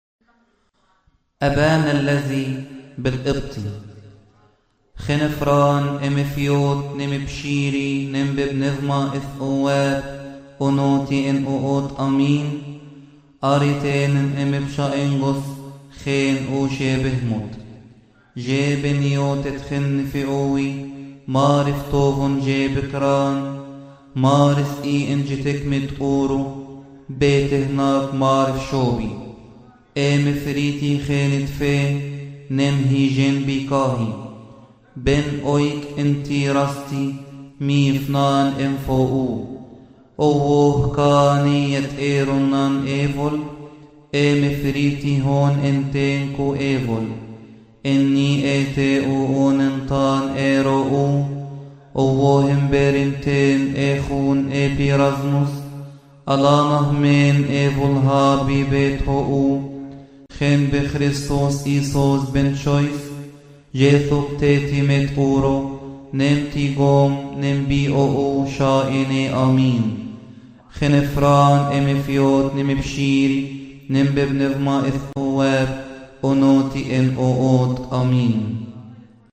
مكتبة الألحان